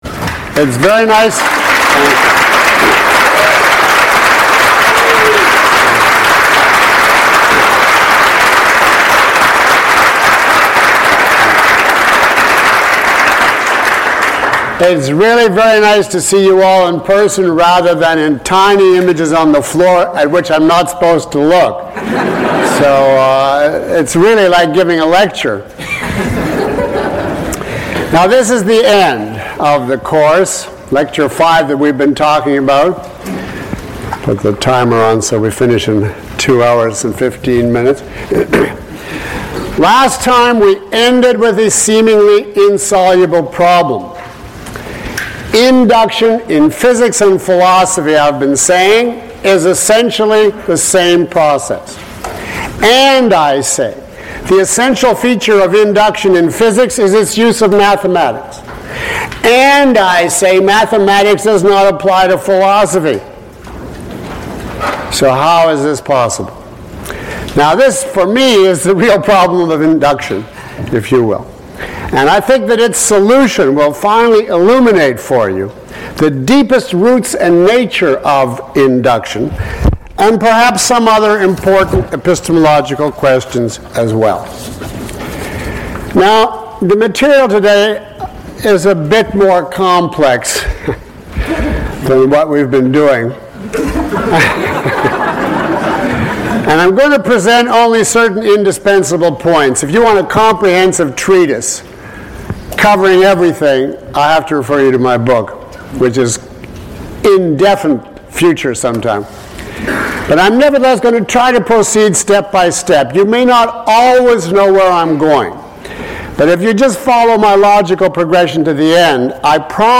Lecture 07 - Induction in Physics and Philosophy.mp3